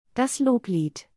/ˈloːpliːt/ · /ˈloːpliːtəs/ · /ˈloːpliːdɐ/